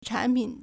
产品 (產品) chǎnpǐn
chan3pin3.mp3